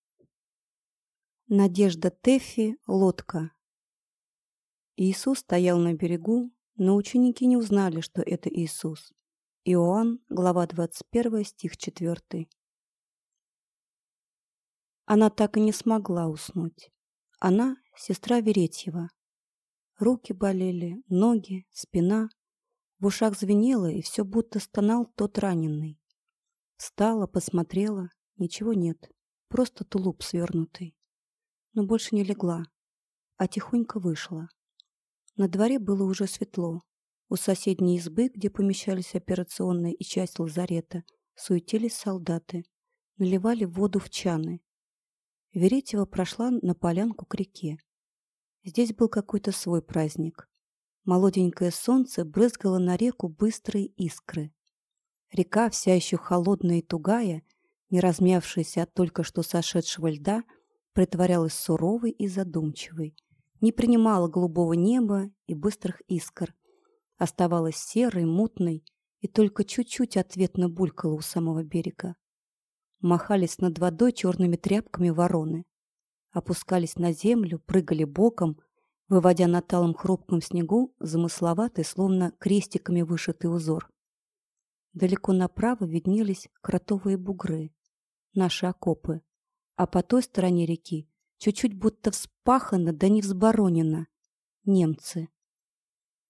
Аудиокнига Лодка | Библиотека аудиокниг